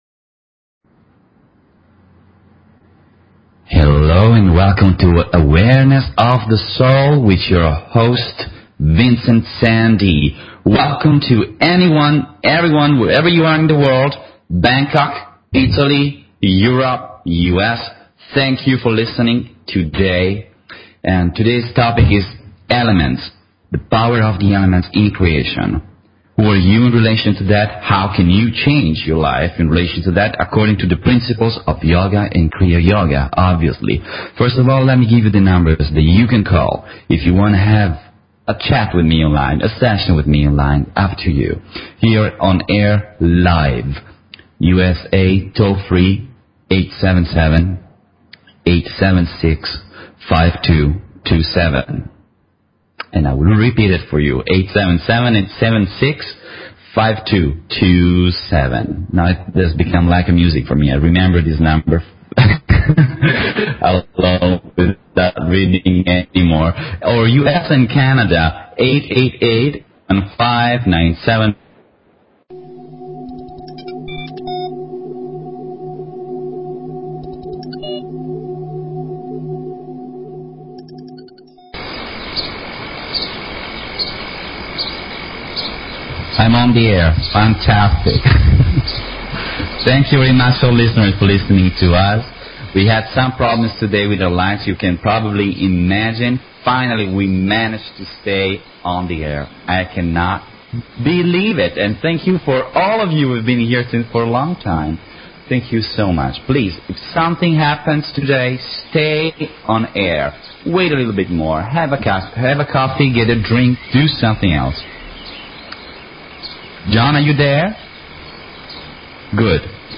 Talk Show Episode, Audio Podcast, Awareness_of_the_Soul and Courtesy of BBS Radio on , show guests , about , categorized as